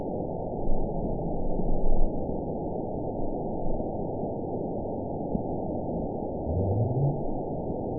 event 916919 date 02/18/23 time 00:23:58 GMT (2 years, 2 months ago) score 9.48 location TSS-AB10 detected by nrw target species NRW annotations +NRW Spectrogram: Frequency (kHz) vs. Time (s) audio not available .wav